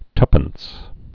(tŭpəns)